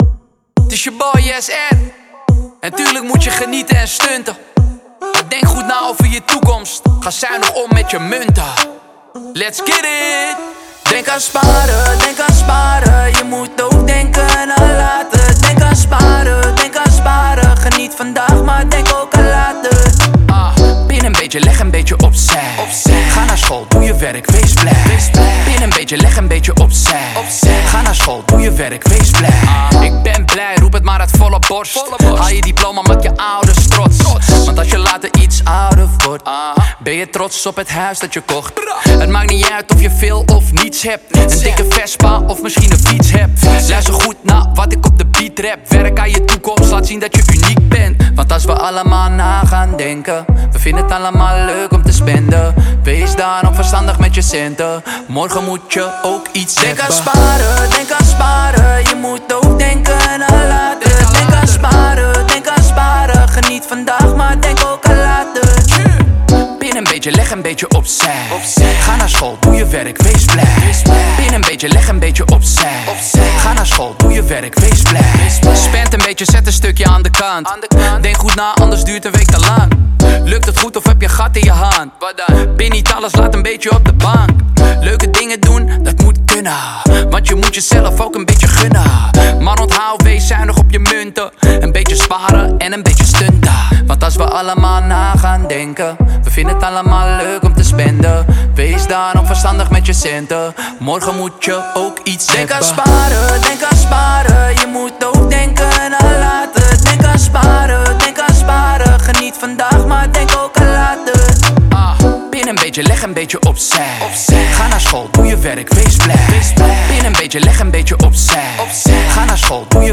Rappen over geld?